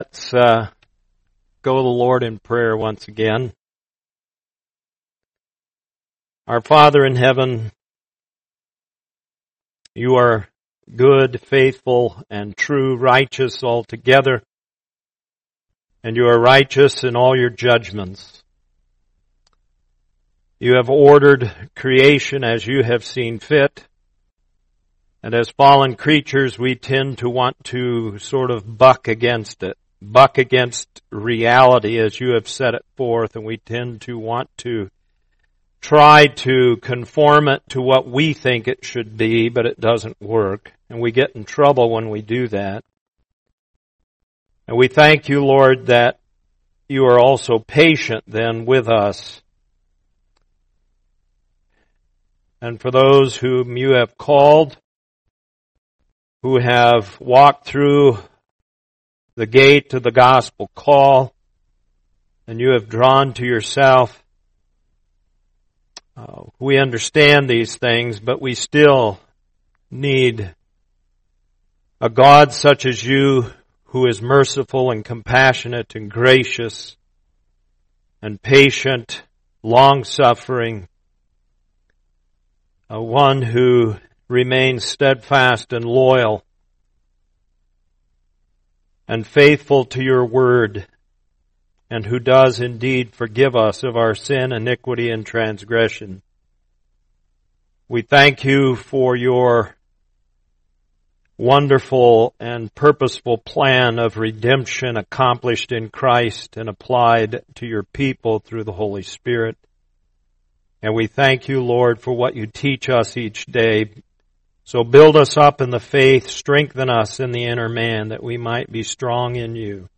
Sermons | Lake Phalen Community Church